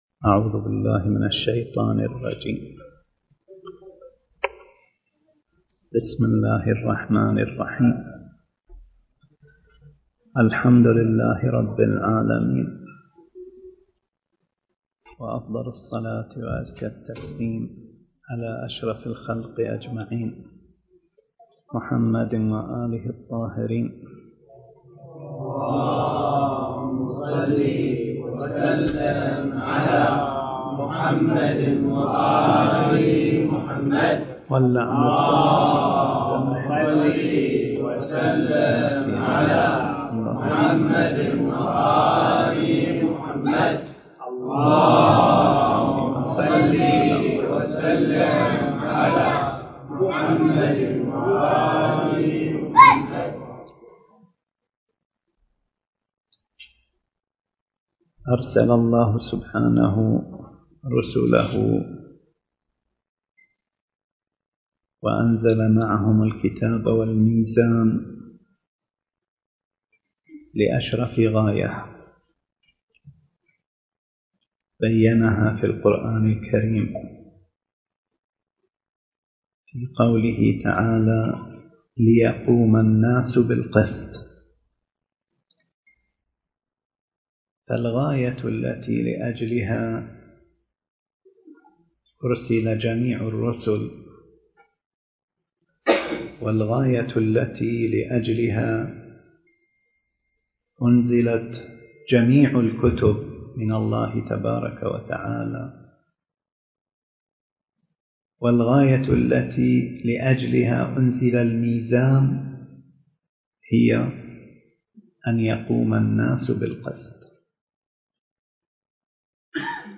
التاريخ: 2018 المكان: حسينية آية الله السيد طاهر السلمان / الاحساء